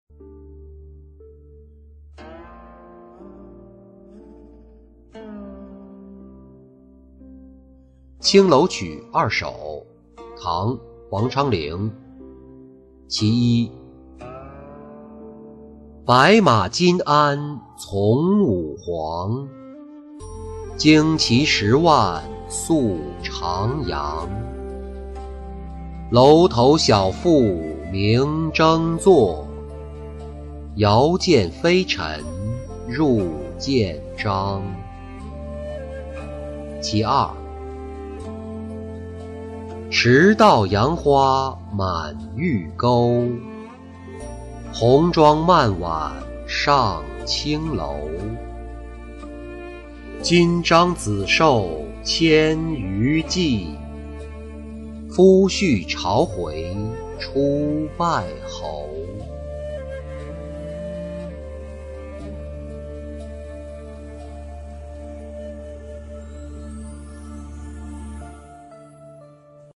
青楼曲二首-音频朗读